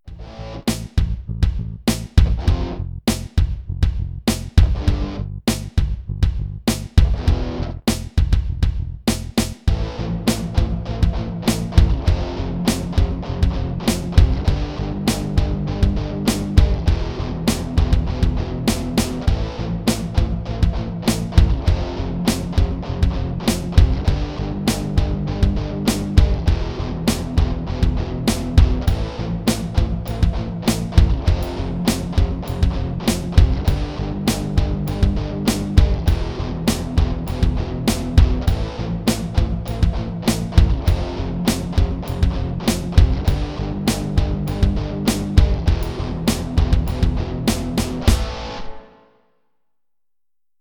The backing track to play along with:
Easy to Play Rock Guitar Solo - Tablature